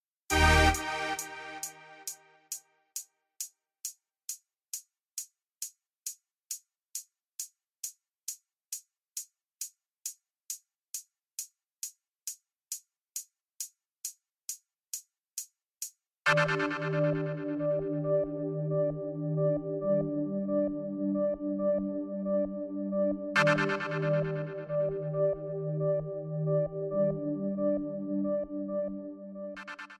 Eb
MPEG 1 Layer 3 (Stereo)
Backing track Karaoke
Pop, 1990s